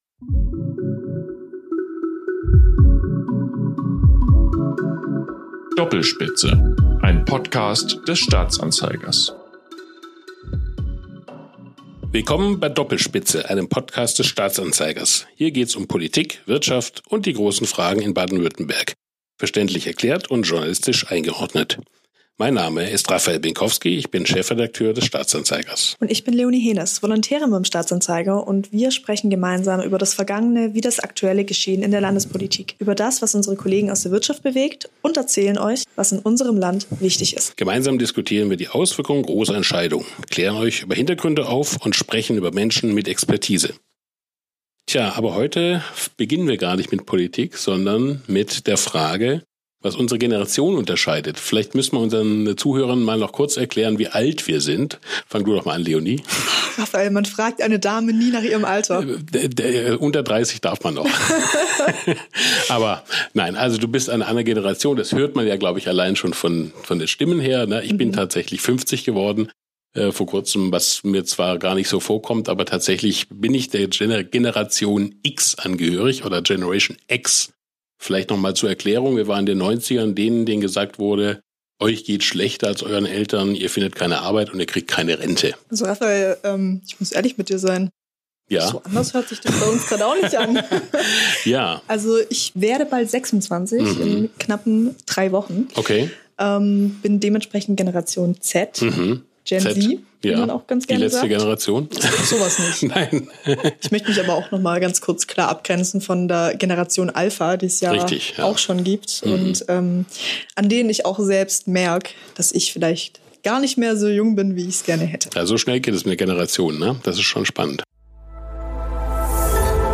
GenZ vs GenX – Der Generationen-Talk ~ Doppelspitze – Ein Podcast des Staatsanzeigers Podcast